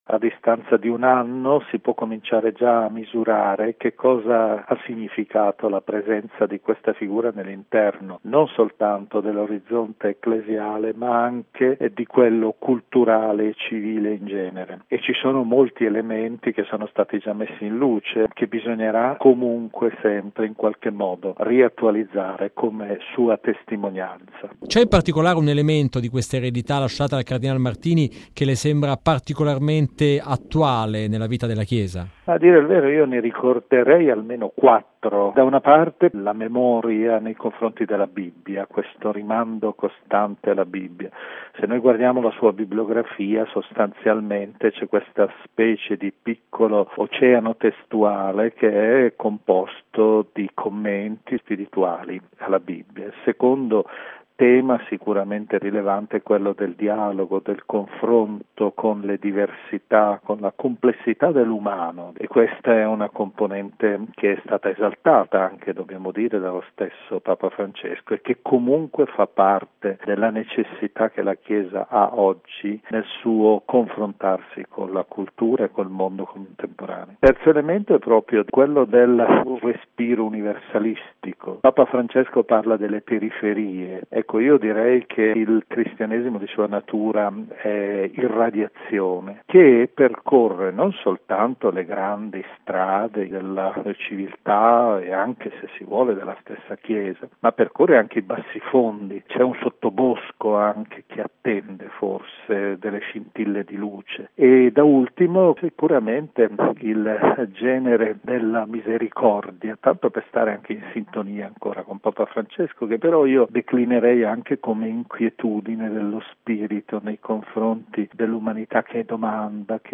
A ricordarne la caratura di vescovo e di studioso è anche il cardinale Gianfranco Ravasi, presidente del Pontificio della Cultura, che a Milano è stato per lunghi anni prefetto della Biblioteca Ambrosiana.